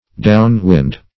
Down-wind \Down"-wind`\, adv.